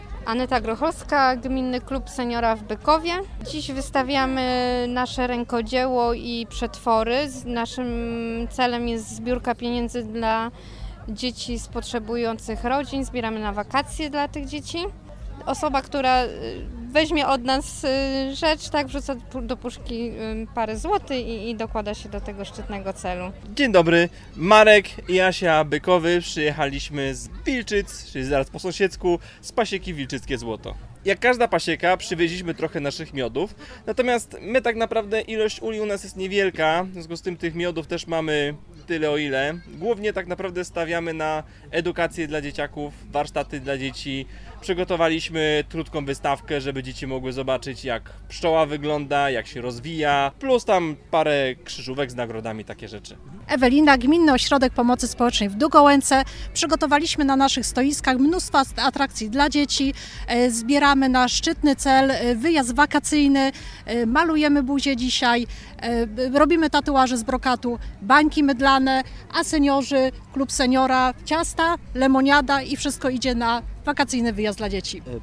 Swoje stanowiska dla odwiedzających przygotowały zarówno podmioty gminne takie jak Gminny Ośrodek Pomocy Społecznej, Gminny Klub Senior+, jak i punkty gastronomiczne i organizatorzy różnego rodzaju warsztatów. Rozmawialiśmy z przedstawicielami kilku z nich.